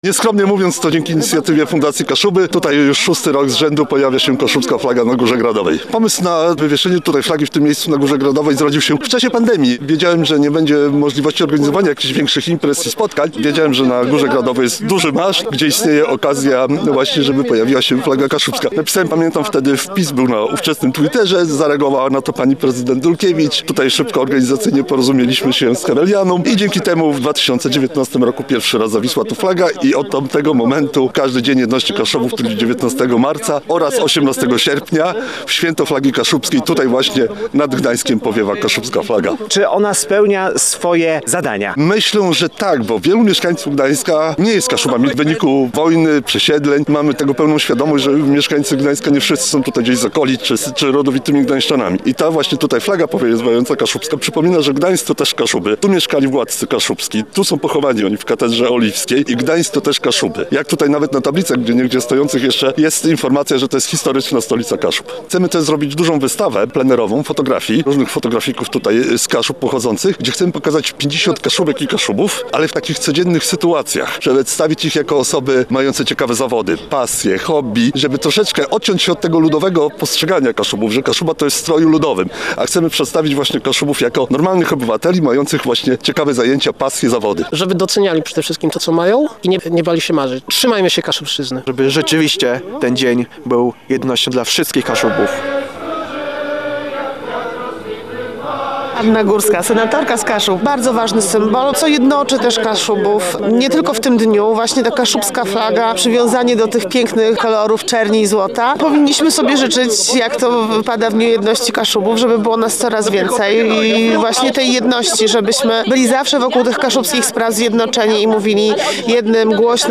Już od 6 lat z tej okazji na Górze Gradowej odbywa się uroczystość zawieszenia czarno-złotej flagi wraz z odśpiewaniem hymnu kaszubskiego.